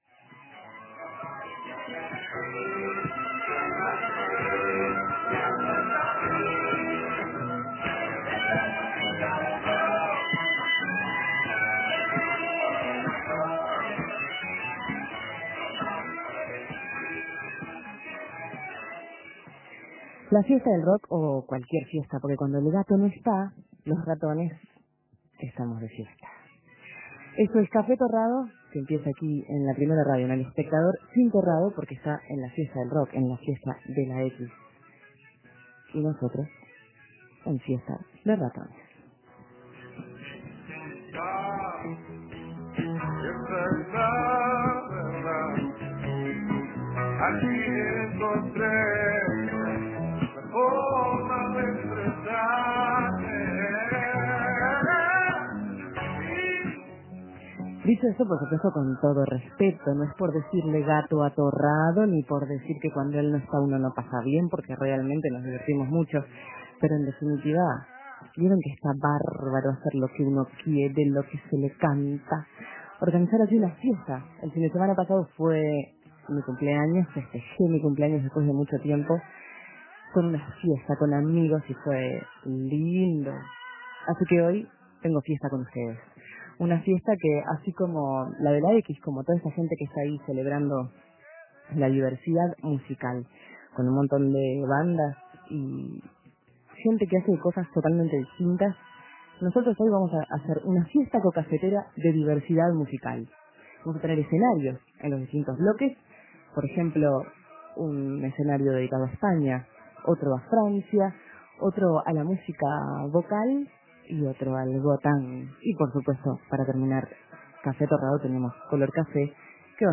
Recitales, música en vivo, fonoplateas.